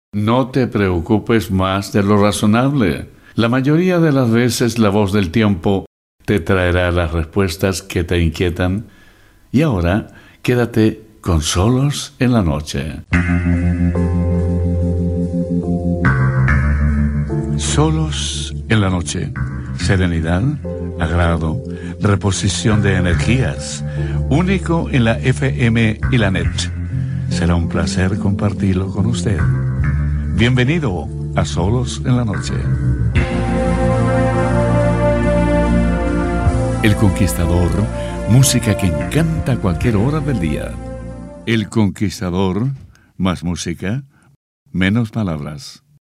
El Conquistador sonaba como los dioses.